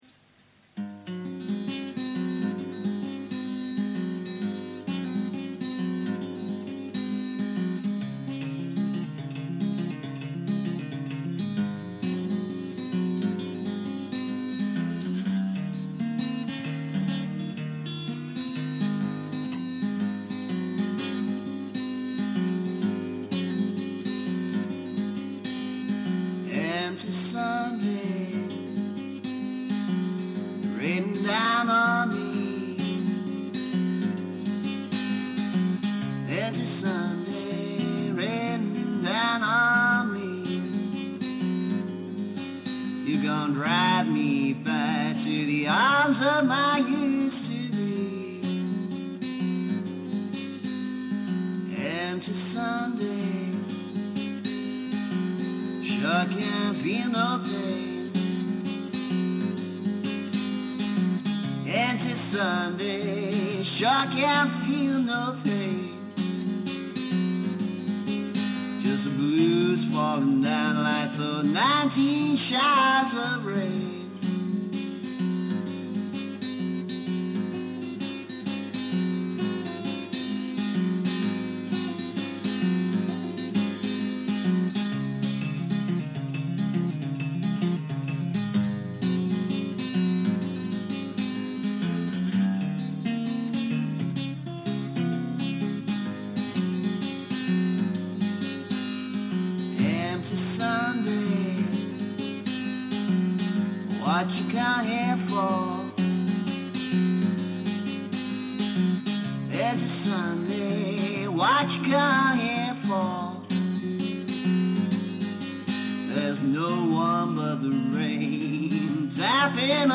A very old recording (from cassette, not a studio recording). Remastering has raised the volume level, but degraded the guitar slightly. One of my bluesier songs.